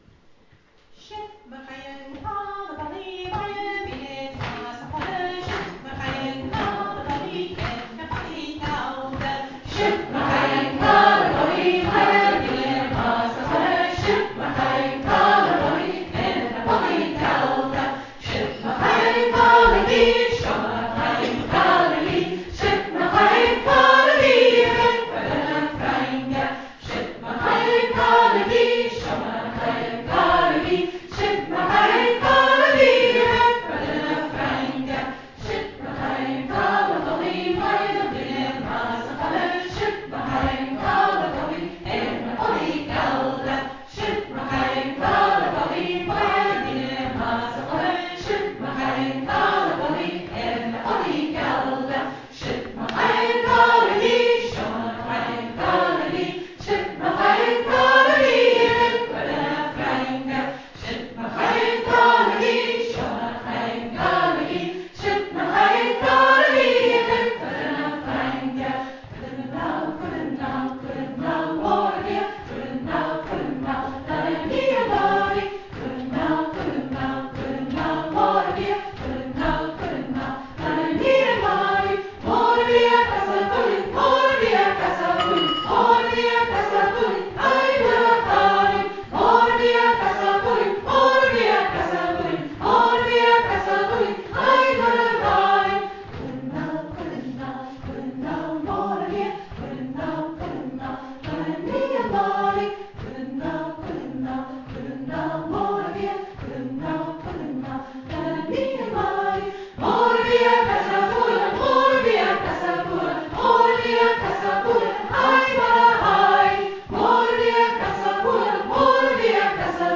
Here are recordings from the cèilidh on Thursday night when we sang a some of the songs we learnt:
Siud mar chaidh an càl a dholaidh & Cuir a nall Mor-a-Bhitheag (That is how the kale was ruined & Send on over Marion-a-Bhitheag) – two puirt à beul or pieces of mouth music, the first of which tells how the kale was ruined by the lowland men and the emperor of France, and the second concerns Marion-a-Bhitheag – the meaning of Bhitheag in this context is uncertain.